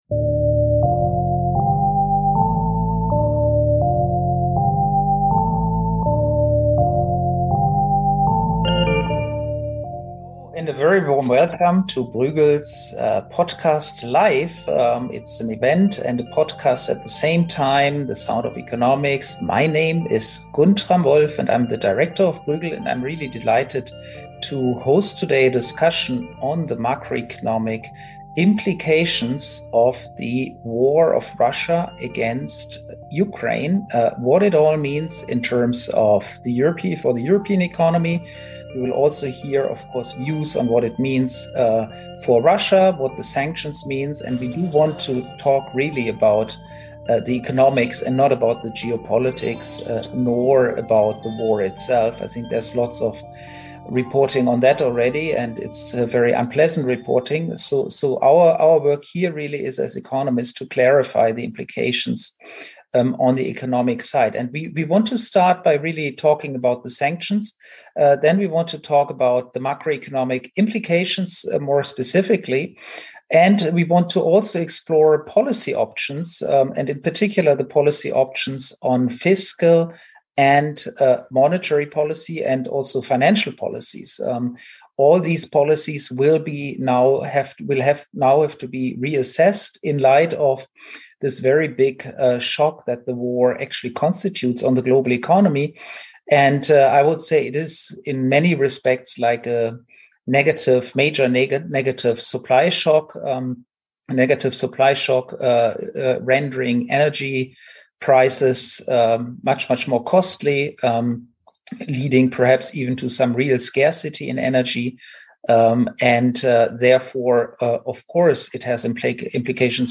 What fiscal and monetary policies will the EU have to consider to get Europe through this new crisis? Guntram B. Wolff invites Luis Garicano and Jean Pisani-Ferry to unpack these and other questions in this exceptional episode of the Sound of Economics Live.